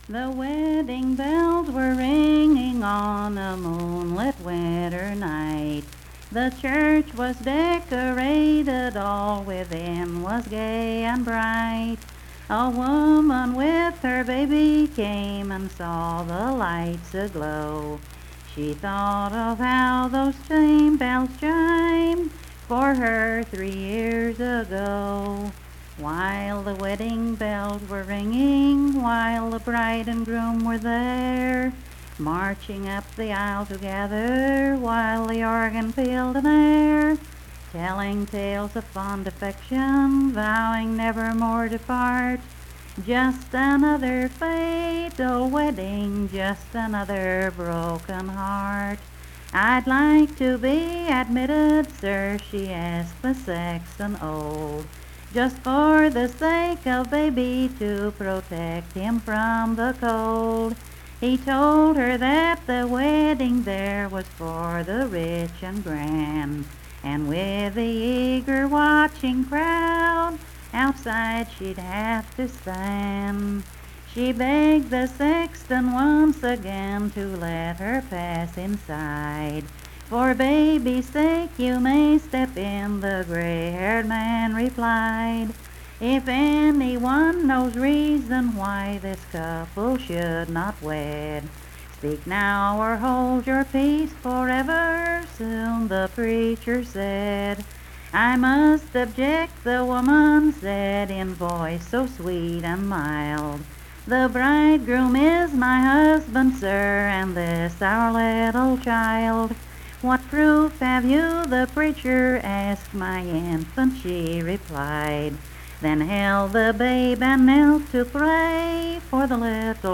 Unaccompanied vocal music
Performed in Coalfax, Marion County, WV.
Voice (sung)